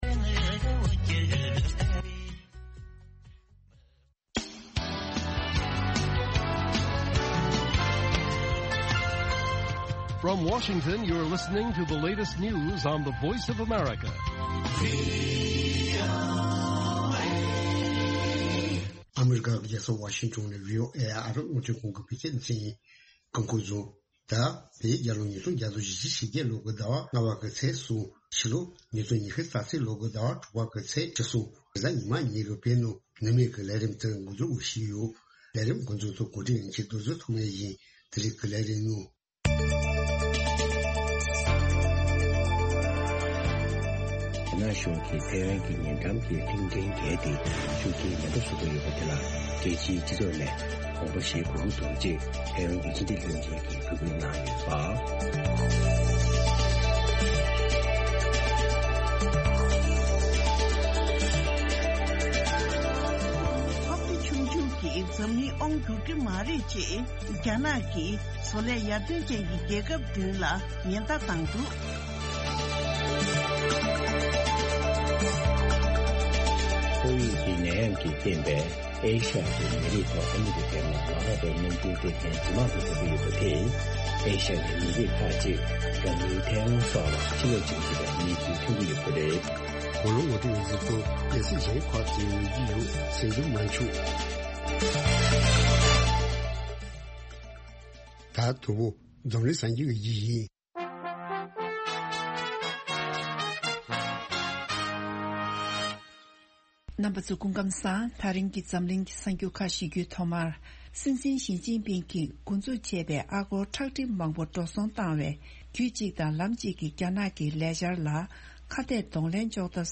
དགོང་དྲོའི་རླུང་འཕྲིན།